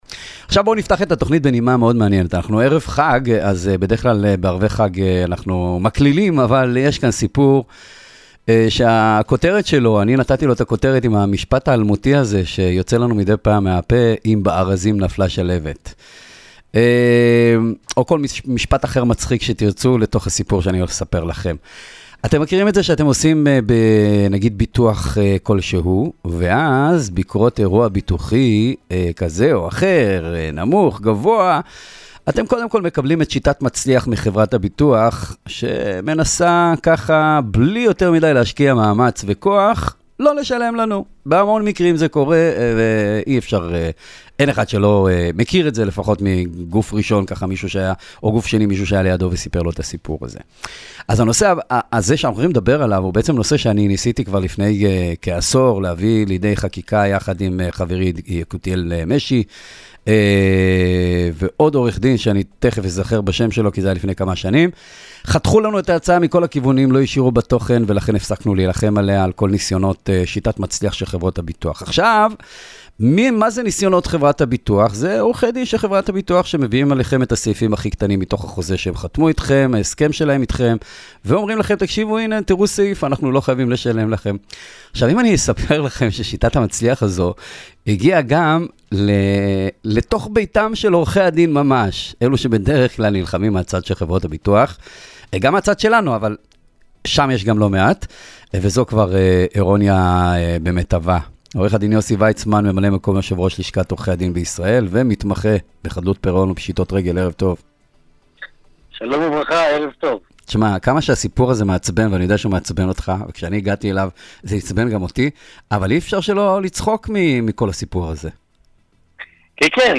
ראיון-קול-ישראל-אחריות-מקצועית-של-עורכי-דין.mp3